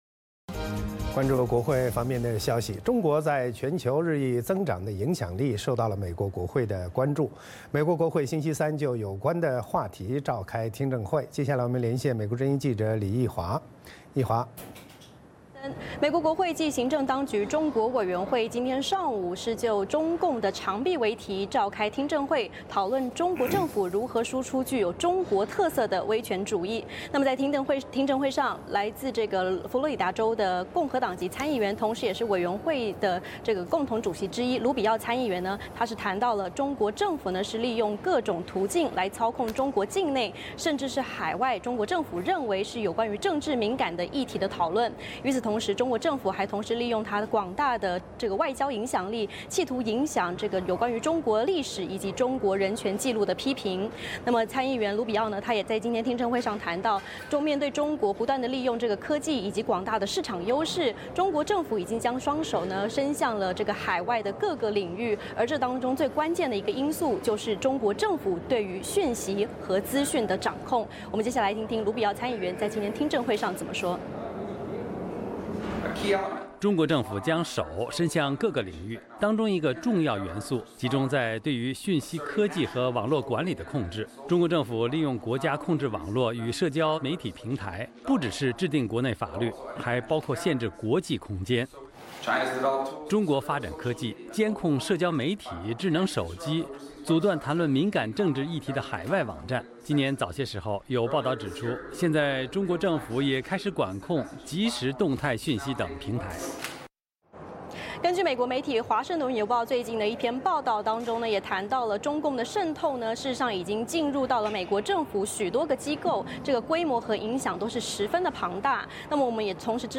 VOA连线：美国会召开听证，讨论“中共之长臂”